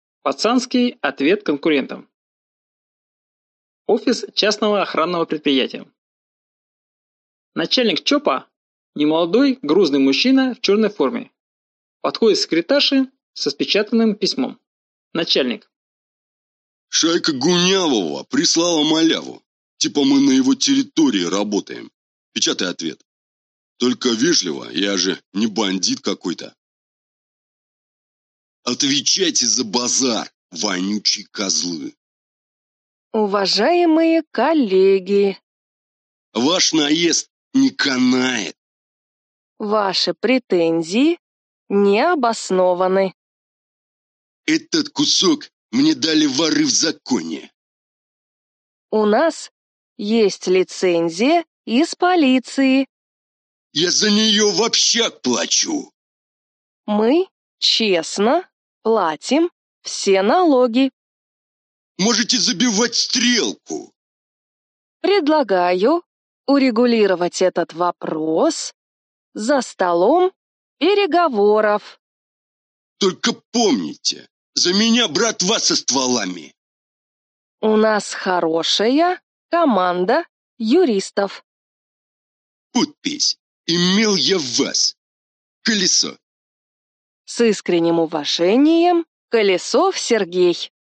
Аудиокнига 100 новых смешных сценок. выпуск 1 | Библиотека аудиокниг